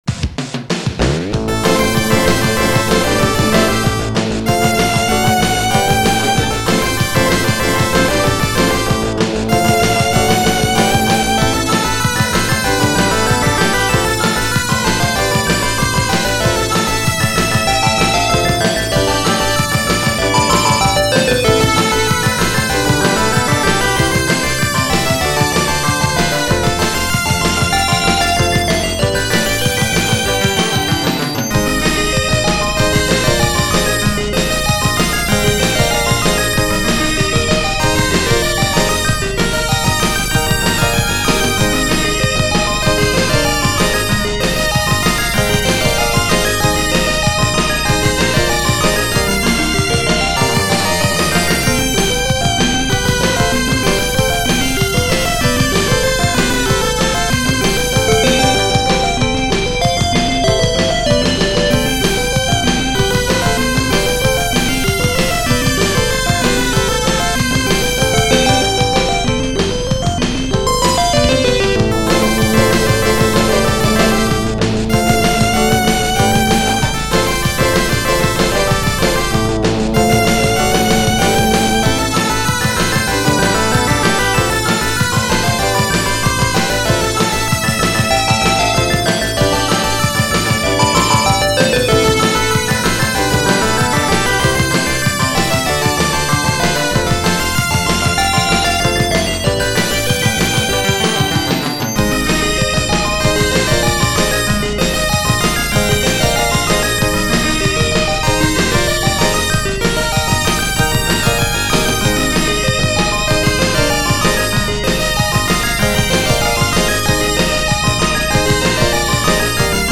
この曲はX68000で打ち込んだものを、Ｗｉｎでエミュレートさせ、それをMP3にコンバートしました。
X68000の内臓音源は、ＦＭ音源が８chに、音声合成ようのＡＤＰＣＭ音源が１chです。
あまり、音楽関係の機材は持っていないので、コンバートの際にノイズが入ってます。
かなり、ノイズが気になりますが、ライン入力による録音ができる環境ではないので、これでよしとします。